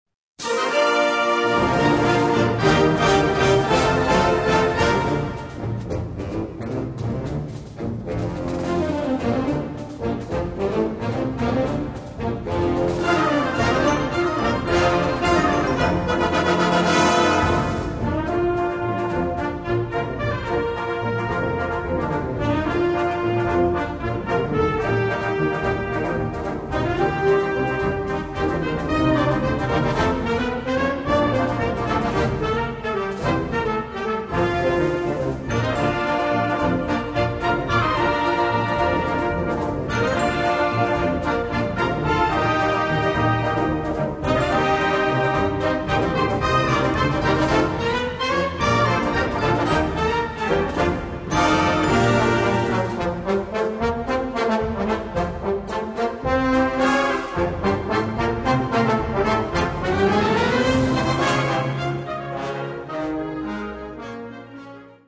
Besetzung: Blasorchester
festlichen Marsch mit originellem Rhythmus